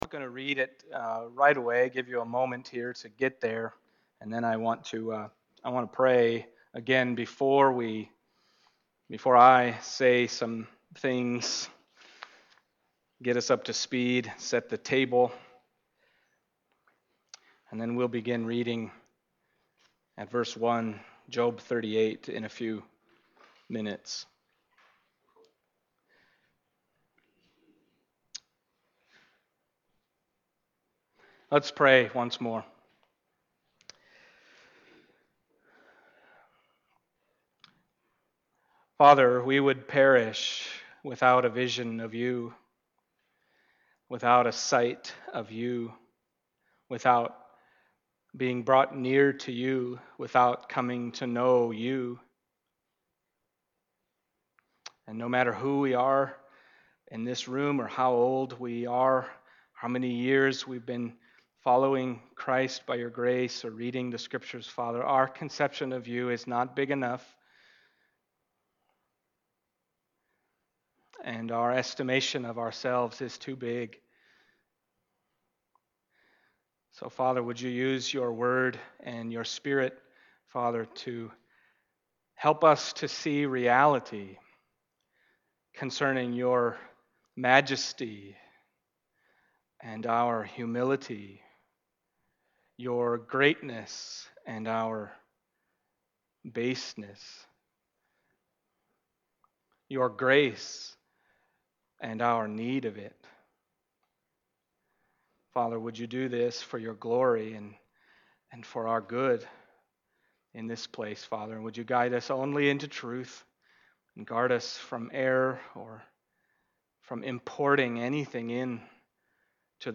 Passage: Job 38:1-40:5 Service Type: Sunday Morning « The Righteous Sufferer Rebuked The Words of God from the Whirlwind